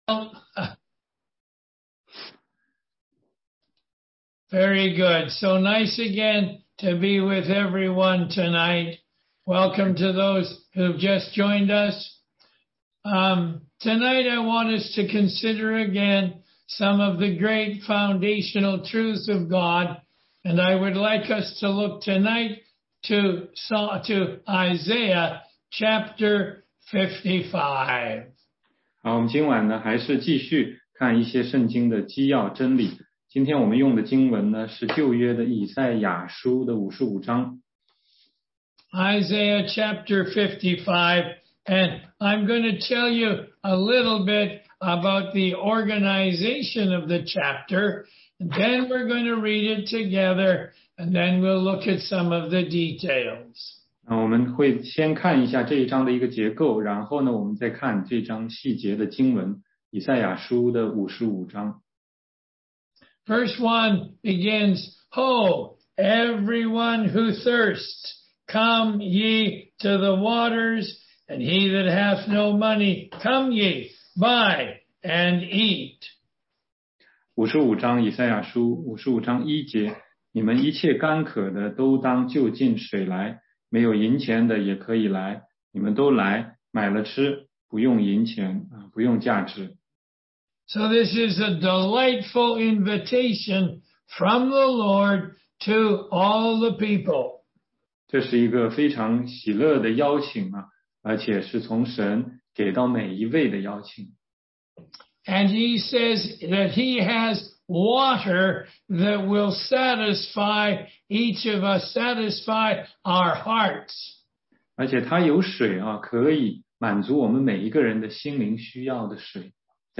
16街讲道录音 - 福音课第三十五讲